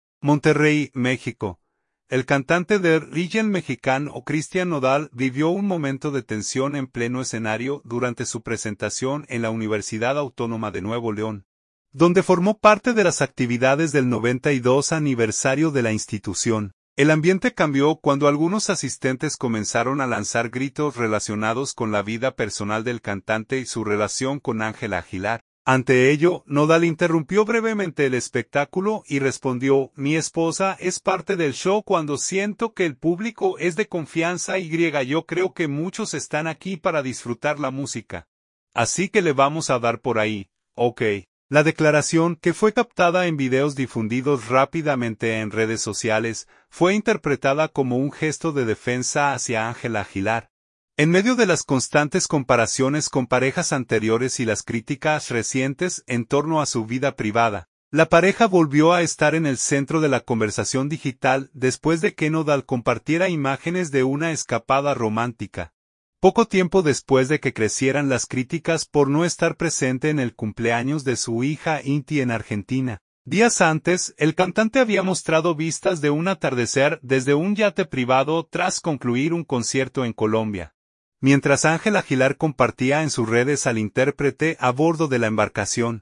Monterrey, México.- El cantante de regional mexicano Christian Nodal vivió un momento de tensión en pleno escenario durante su presentación en la Universidad Autónoma de Nuevo León, donde formó parte de las actividades del 92 aniversario de la institución.
El ambiente cambió cuando algunos asistentes comenzaron a lanzar gritos relacionados con la vida personal del cantante y su relación con Ángela Aguilar.